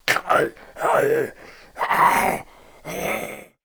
DayZ-Epoch / SQF / dayz_sfx / zombie / idle_23.ogg